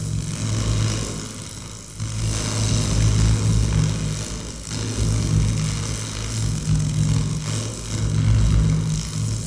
rzr_electric2.wav